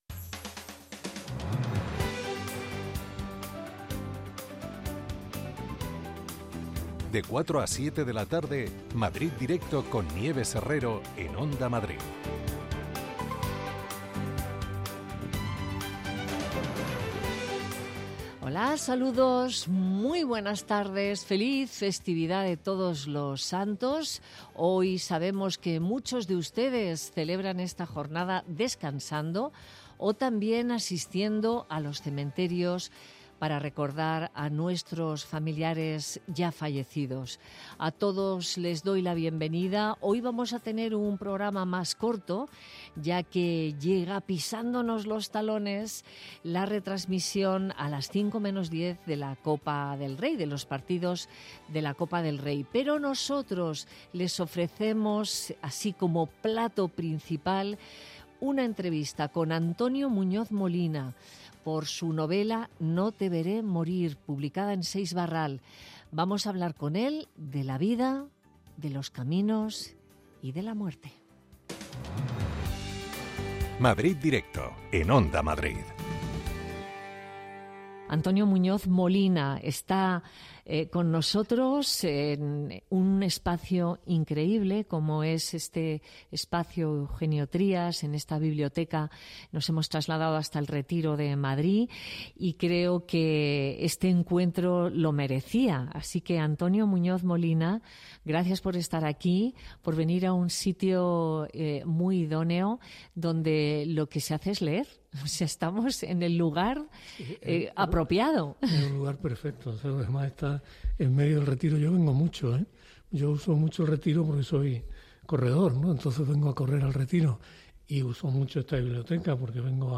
Tres horas de radio donde todo tiene cabida.